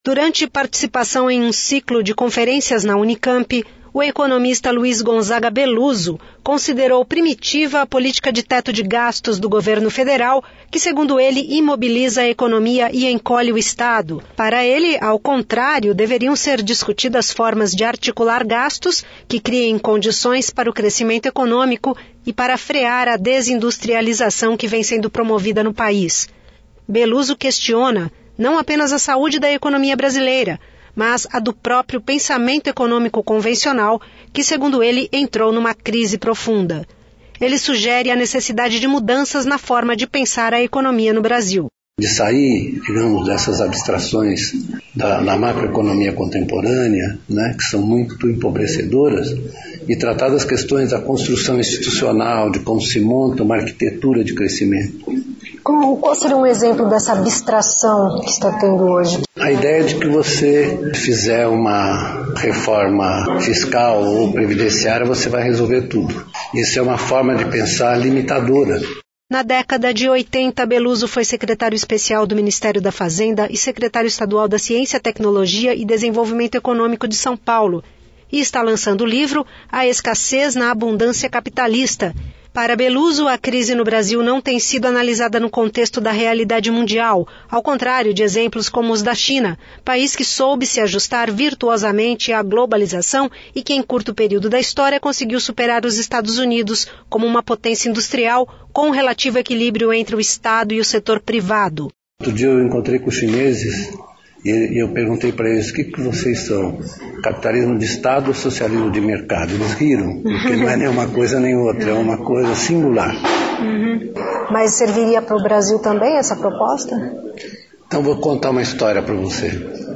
Durante participação em um ciclo de conferências na Unicamp, o economista Luiz Gonzaga Belluzzo, considerou primitiva a política de teto de gastos do Governo Federal, que, segundo ele, imobiliza a economia e encolhe o Estado.